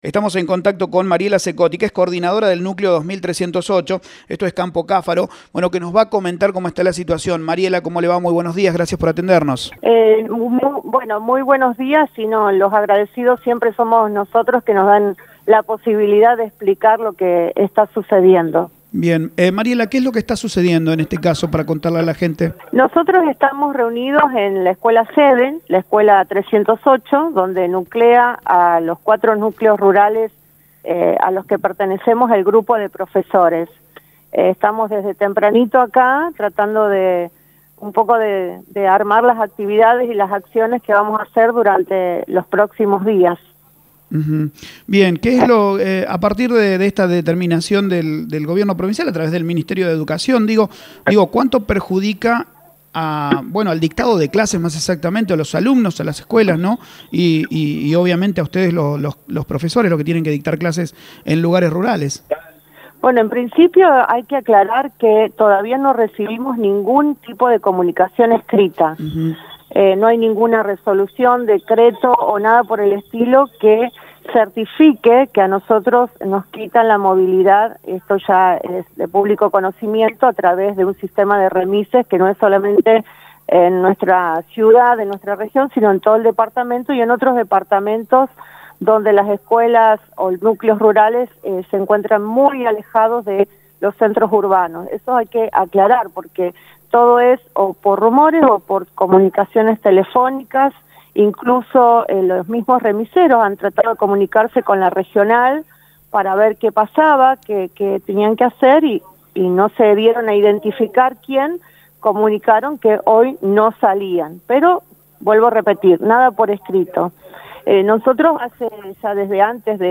RADIO EME CERES habló en vivo